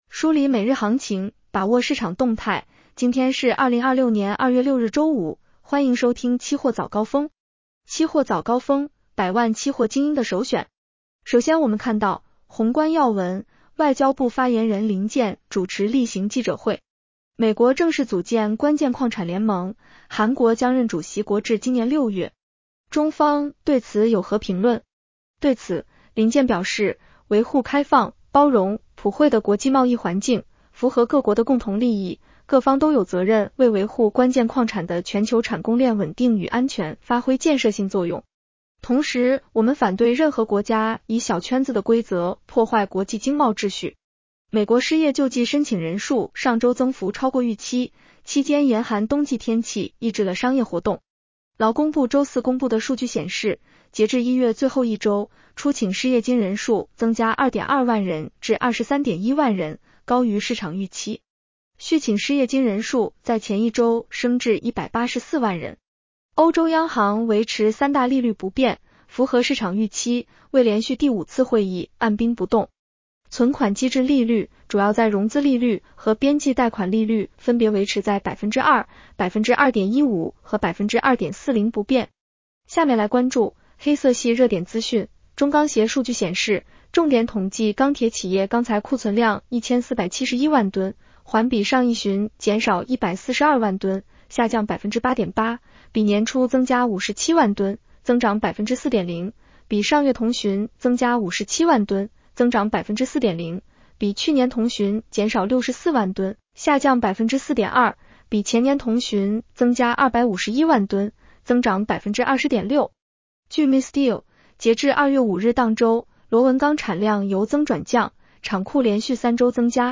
期货早高峰-音频版 女声普通话版 下载mp3 热点导读 1.上期所调整黄金、白银期货相关合约涨跌停板幅度和交易保证金比例。